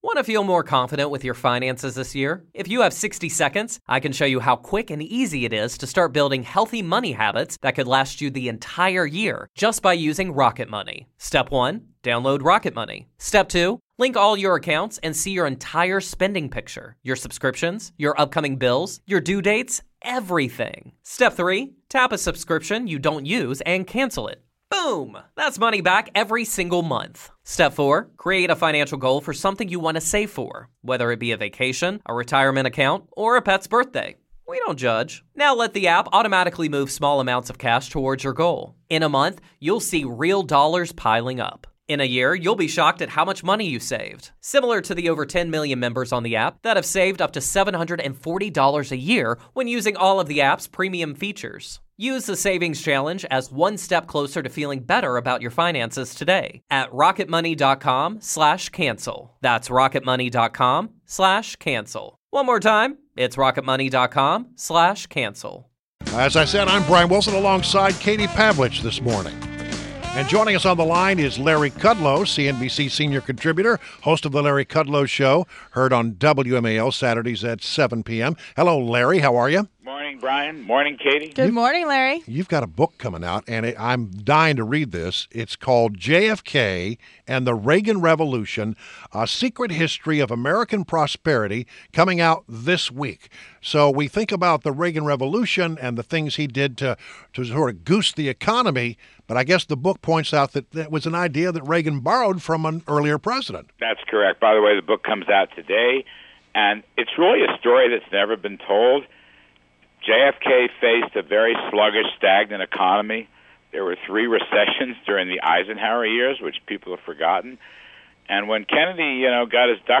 WMAL Interview - LARRY KUDLOW - 09.06.16